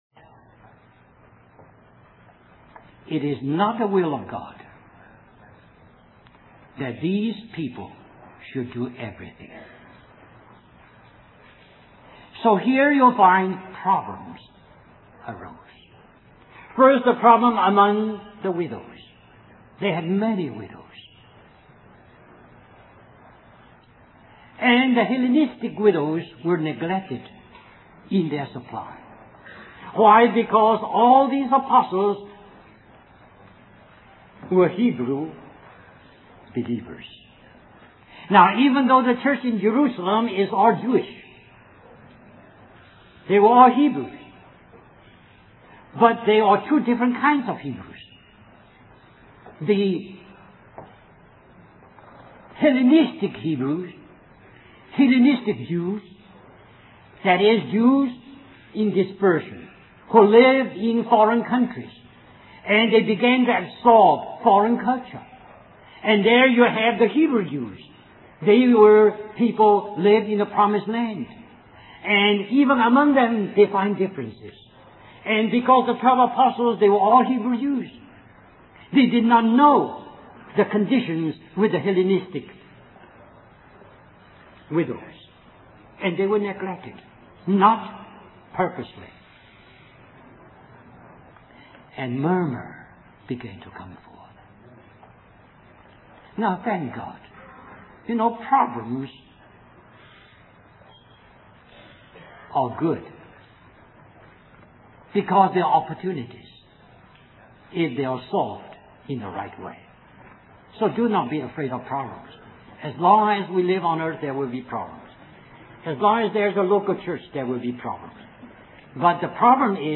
1991 Christian Family Conference Stream or download mp3 Summary This message is a continuation of the message found here .